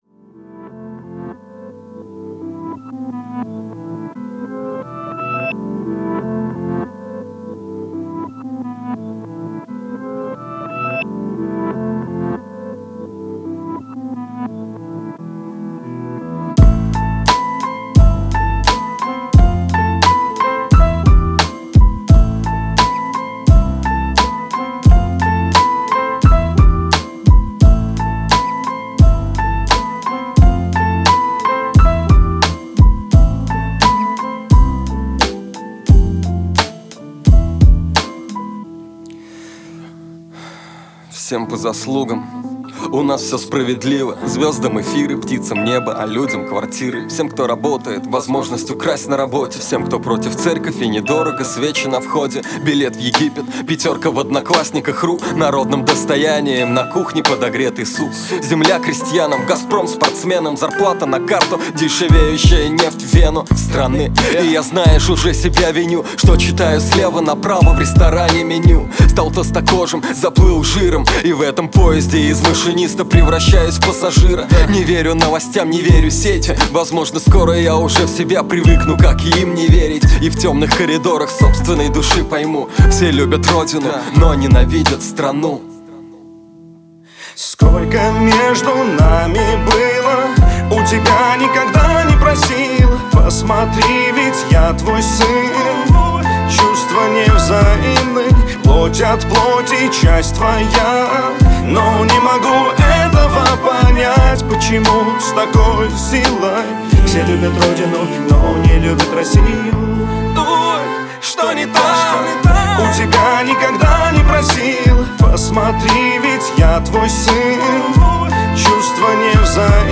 Категория: Рэп (Хип-хоп)